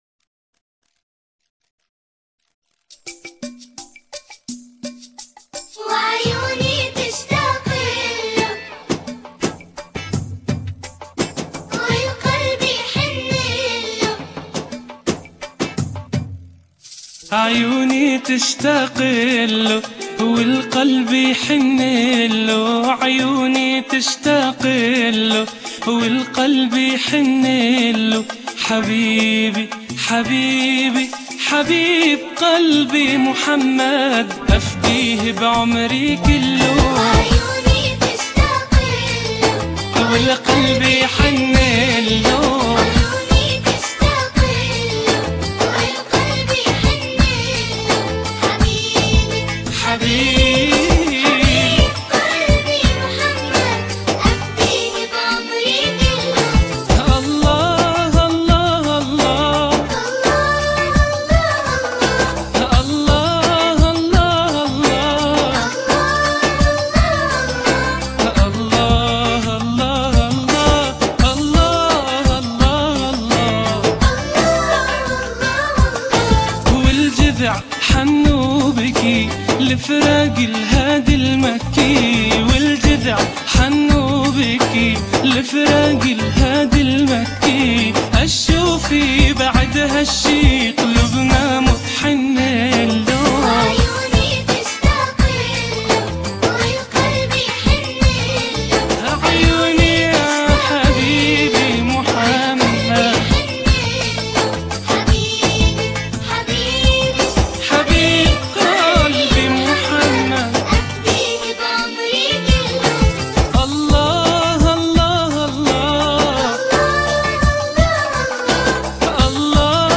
عيوني تشتاقيلوا.mp3 انشودة للصغار(4 Mo) عدد مرات التنزيل 43
منتدى فـلـيـح :: القسم الاسلامي :: اناشيد اسلاميه للاطفال